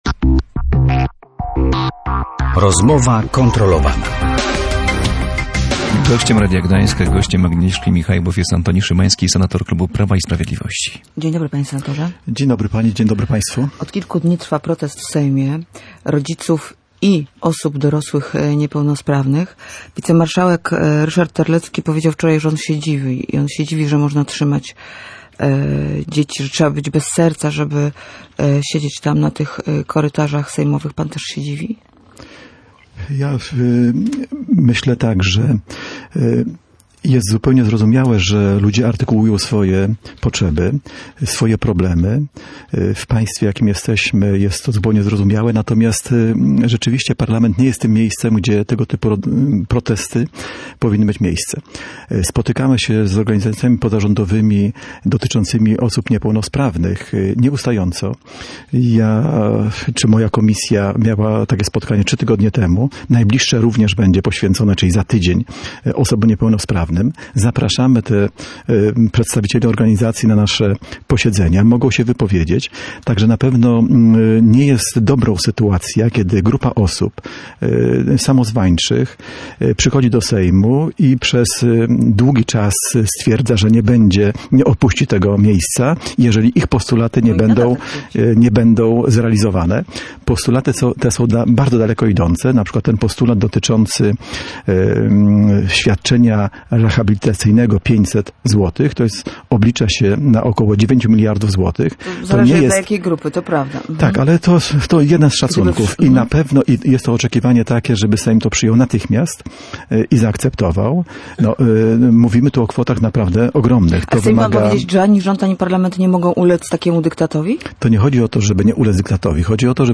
Sąd nie zgodził się również na przekazanie Alfiego lekarzom z Włoch, którzy są pewni, że dziecku da się jeszcze pomóc. – To błędna decyzja – stwierdził na antenie Radia Gdańsk senator PiS Antoni Szymański.